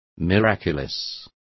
Complete with pronunciation of the translation of miraculous.